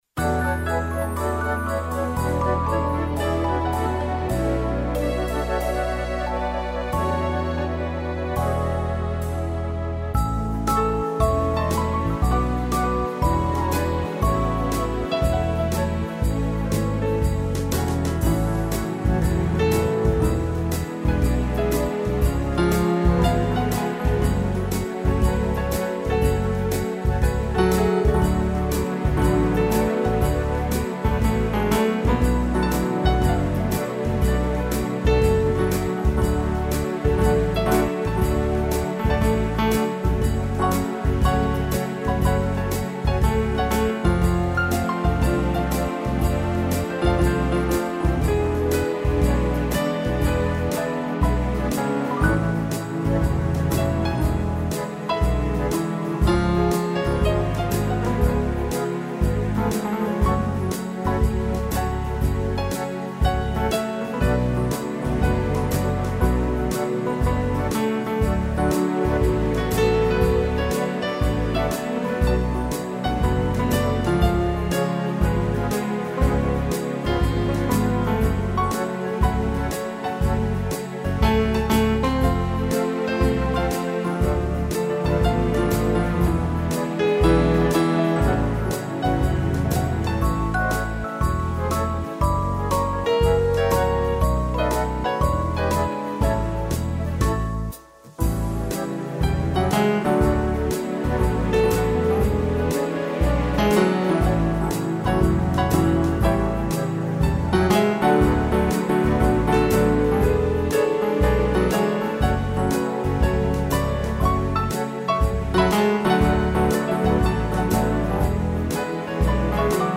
piano
(instrumental)